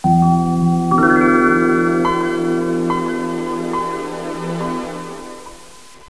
cleared melody on the background of some light noise.
The effect is gained by applying the high-resolving digital signal processing techniques to acoustic signals captured and digitalized by a special receiver.